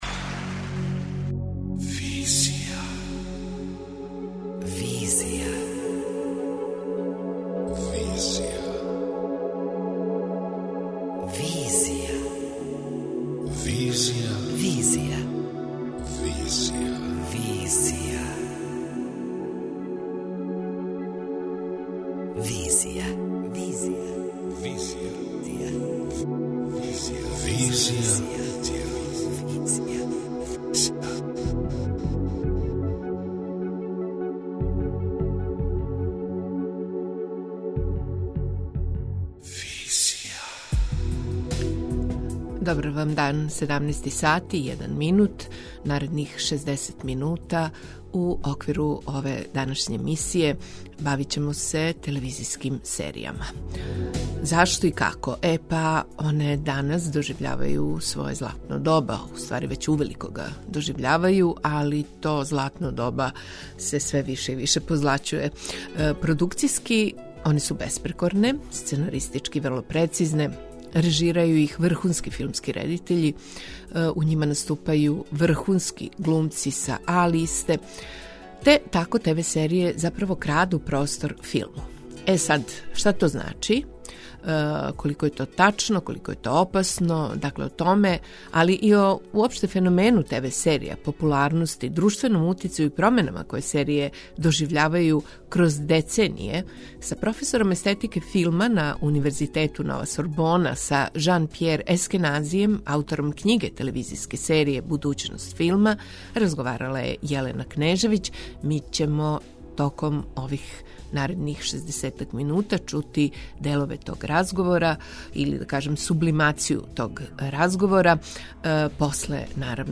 преузми : 26.96 MB Визија Autor: Београд 202 Социо-културолошки магазин, који прати савремене друштвене феномене.